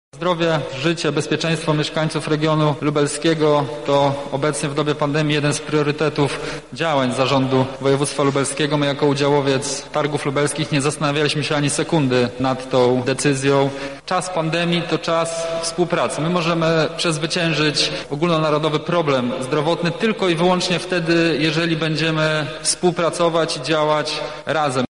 -mówi Wicemarszałek Województwa Lubelskiego Michał Mulawa.